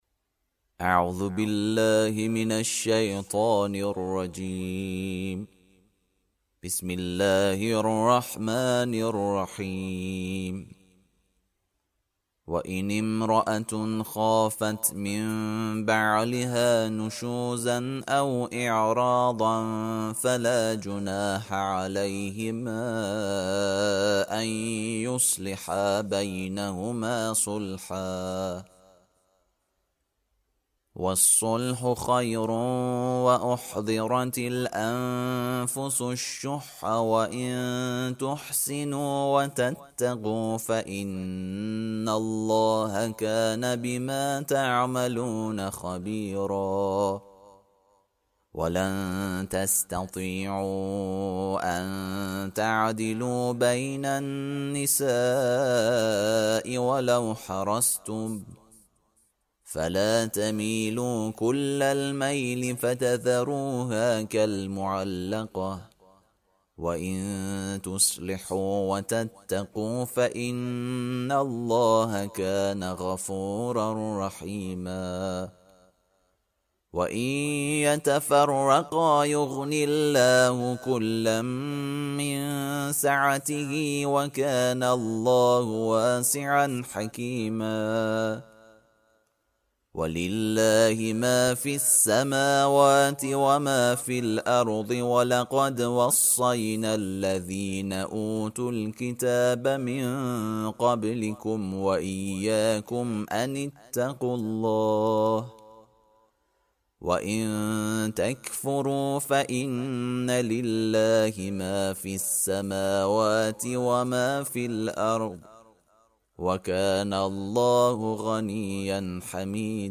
ترتیل سوره(نساء)